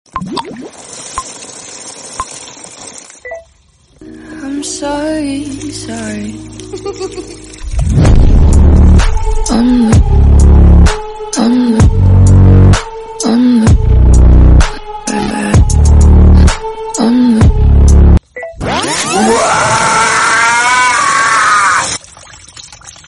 Part 1 – Visual ASMR sound effects free download